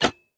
Minecraft Version Minecraft Version 1.21.5 Latest Release | Latest Snapshot 1.21.5 / assets / minecraft / sounds / block / copper_trapdoor / toggle2.ogg Compare With Compare With Latest Release | Latest Snapshot
toggle2.ogg